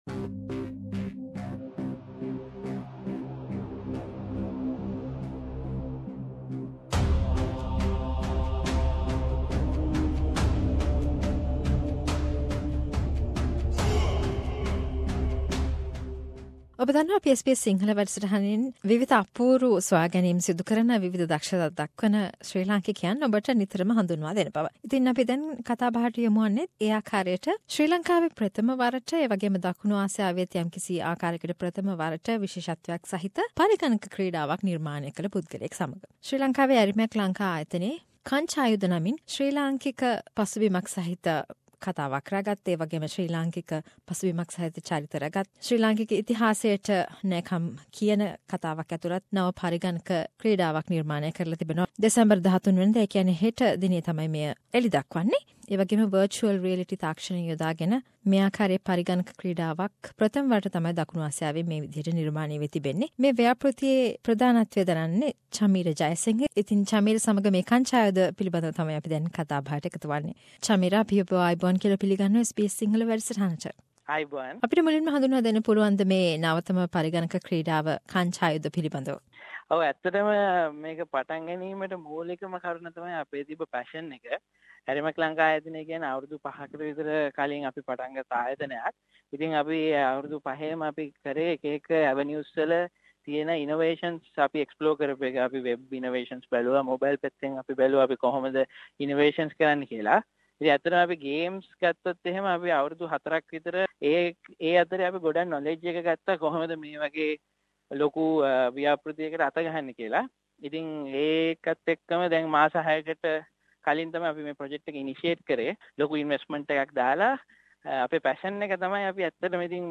A chat